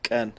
Velar ejective stop
The velar ejective is a type of consonantal sound, used in some spoken languages.
Amharic ቀን/ḳän
[kʼɜn] 'day'
ቀን_qen.wav.mp3